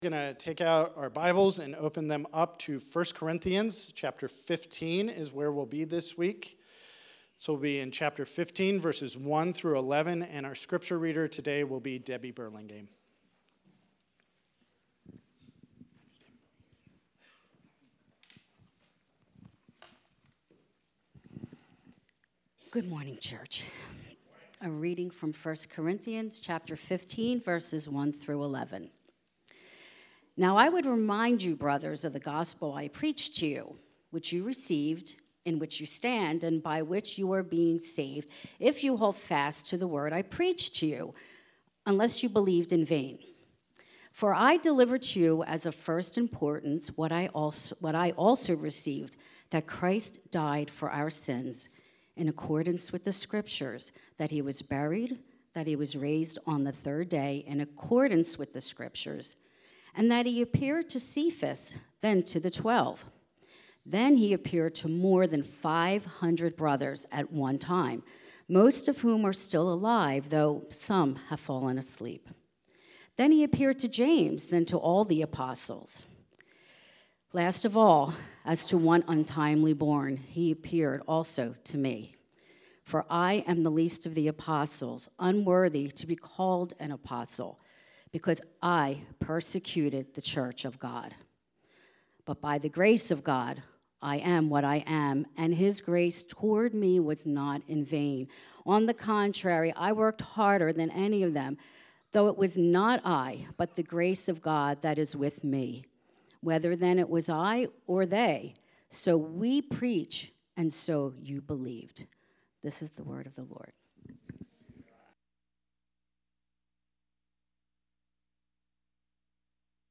Sermons - Grace City Church of the Northeast